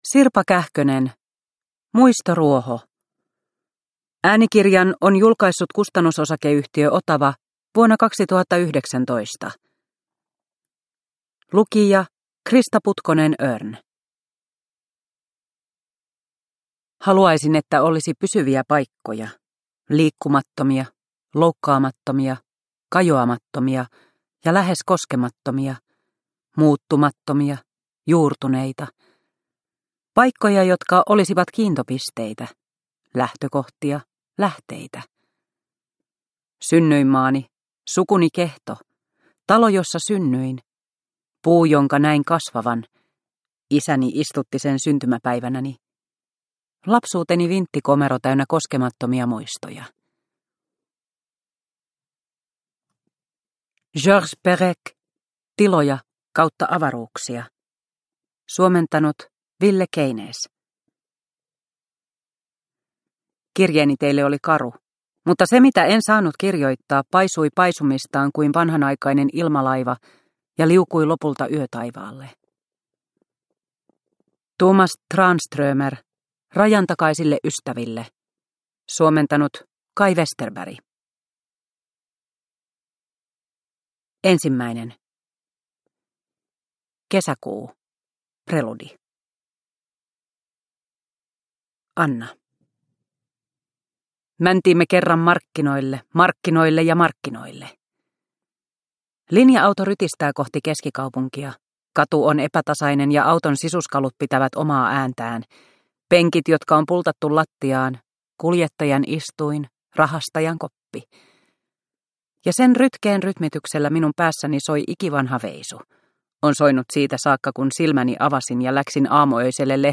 Muistoruoho – Ljudbok – Laddas ner